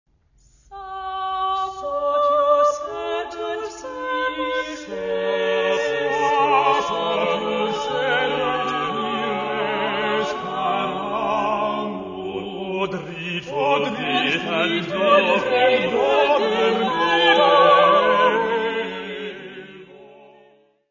Mary's music : Songs and dances from the time of Mary Queen of Scots
Área:  Música Clássica